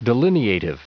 Prononciation du mot delineative en anglais (fichier audio)
Prononciation du mot : delineative